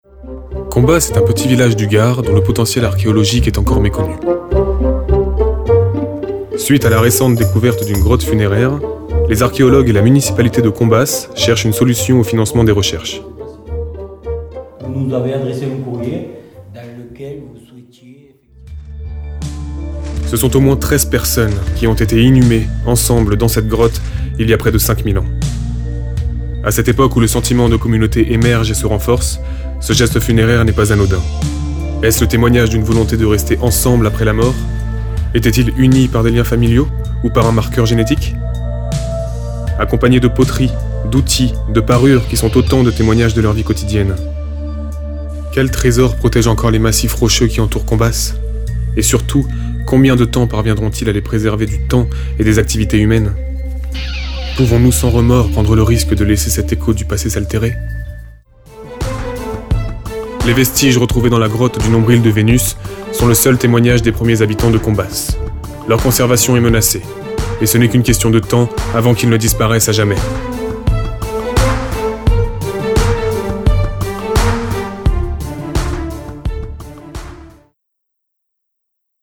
Démo-voix Documentaire archéologie